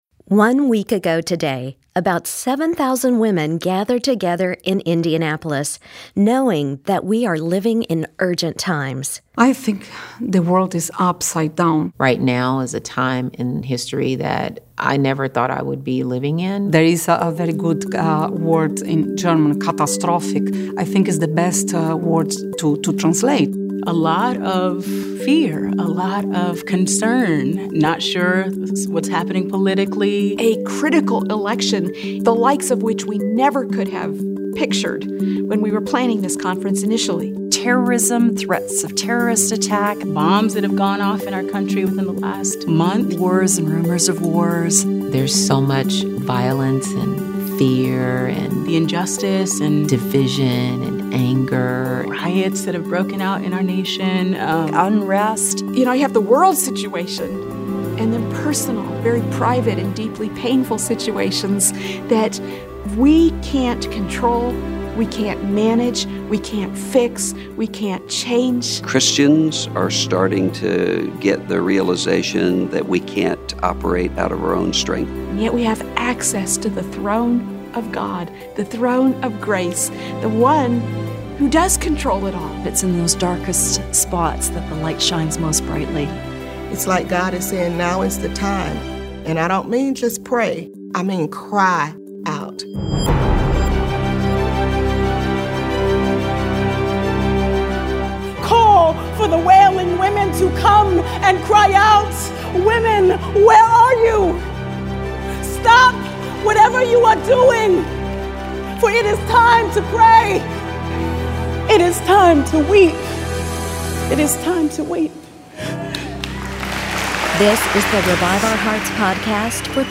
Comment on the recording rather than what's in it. Last week, women from across the nation gathered to cry out to the Lord. Today, you’ll know what it was like to be there.